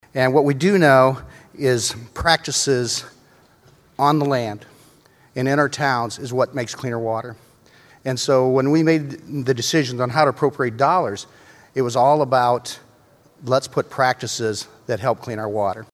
Zumbach says the water quality funds shifted to the Iowa Department of Agriculture will be spent on science-based initiatives.